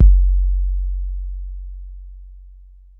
MB Kick (33).wav